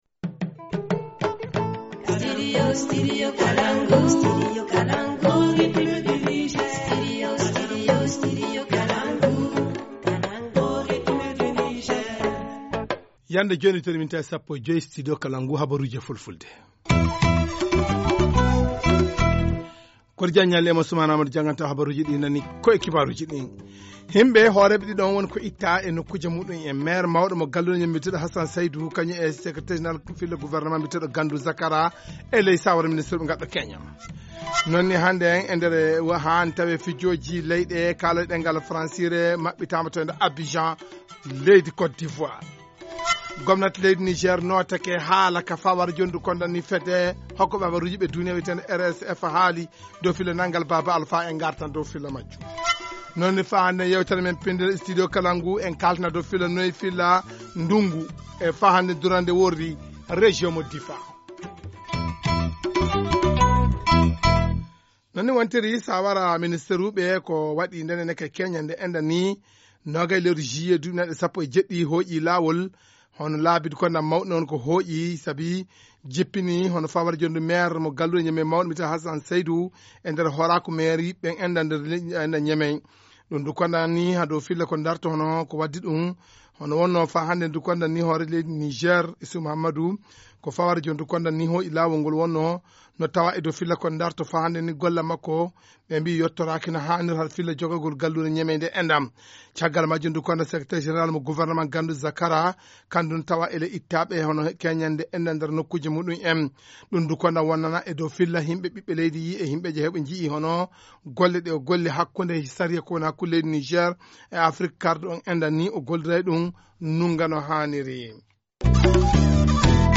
Journal du 21 juillet 2017 - Studio Kalangou - Au rythme du Niger